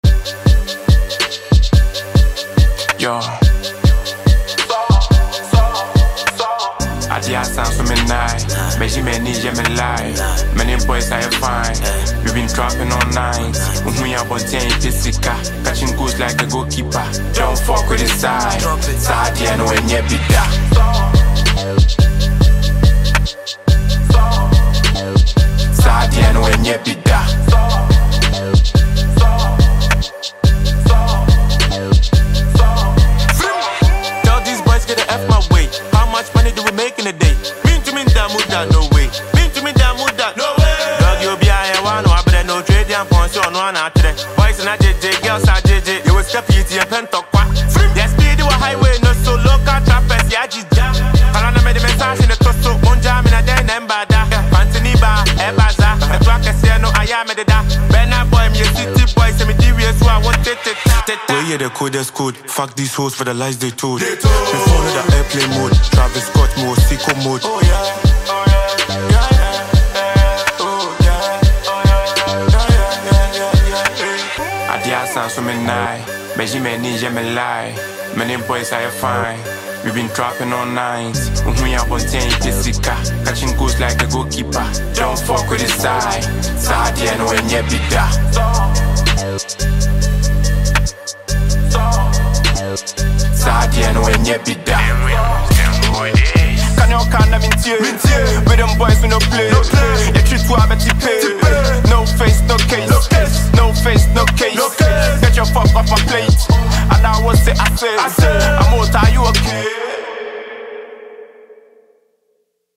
talented Ghanaian rapper and songwriter